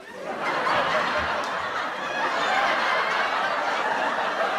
smiech_oNnYGeW.mp3